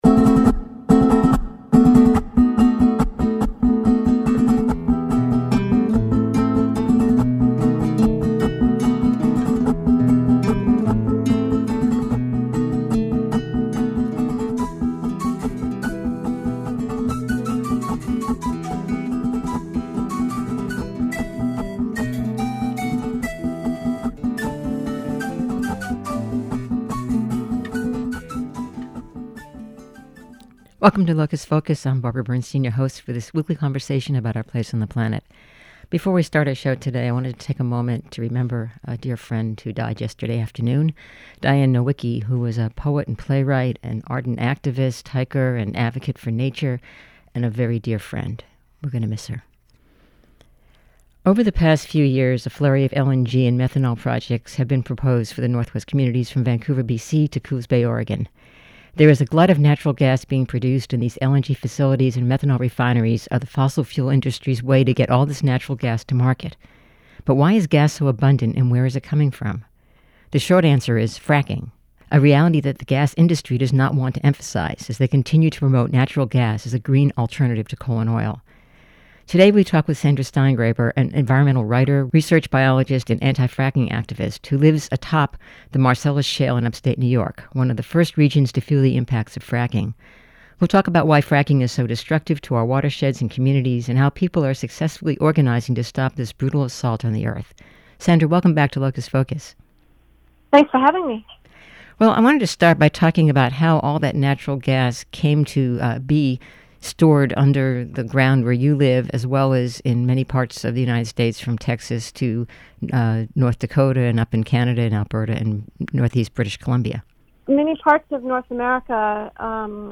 On this episode of Locus Focus, we talk with Sandra Steingraber, an environmental writer, research biologist and anti-fracking activist, who lives a top the Marcellus Shale in Upstate New York, one of the first regions to feel the impacts of fracking. We'll talk about why fracking is so destructive to our watersheds and communities and how people are successfully organizing to stop this brutal assault on the earth.